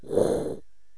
evil_dragon_fly.wav